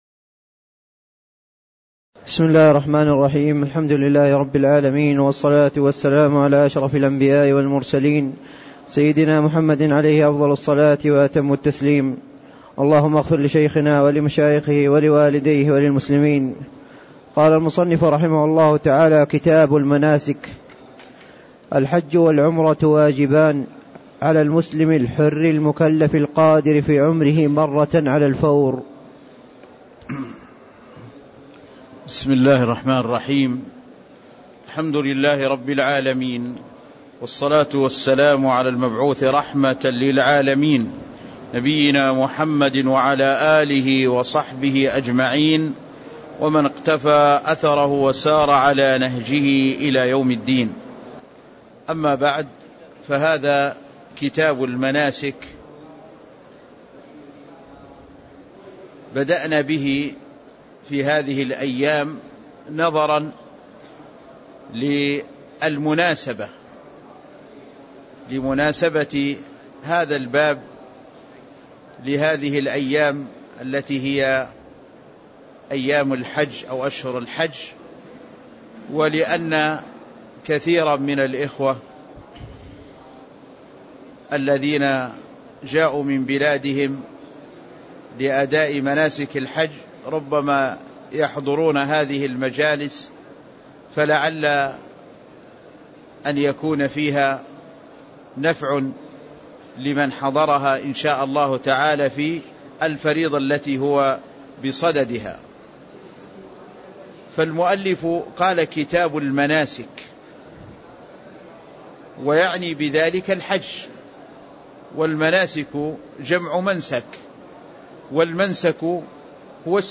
تاريخ النشر ١٤ ذو القعدة ١٤٣٥ هـ المكان: المسجد النبوي الشيخ